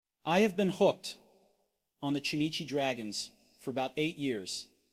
→ /aɪ hæv bɪn hʊkt ɑn ðə tʃuːˈniːtʃi ˈdræɡənz fər əˈbaʊt eɪt jɪrz./